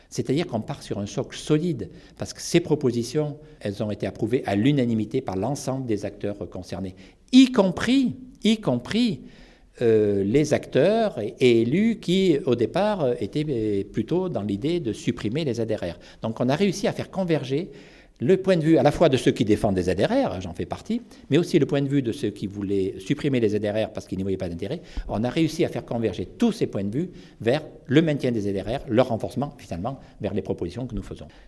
Il répond aux questions de Radio Totem.